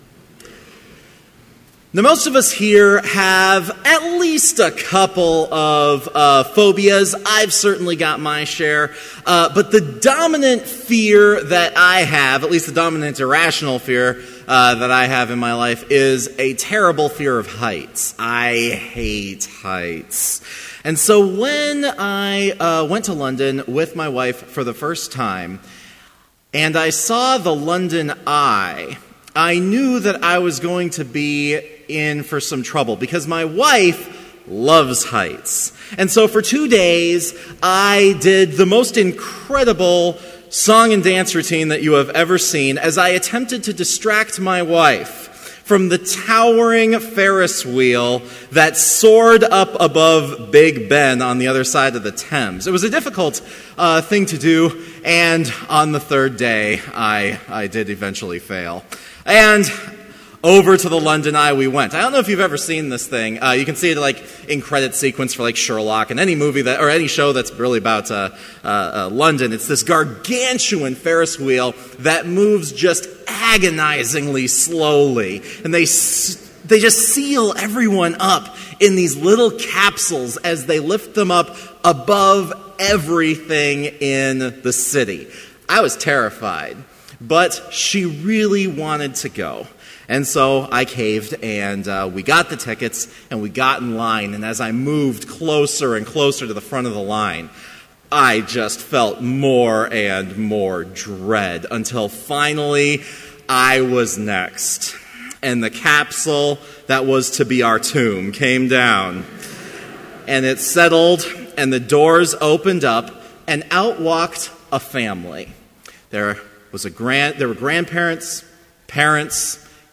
Complete service audio for Chapel - May 1, 2014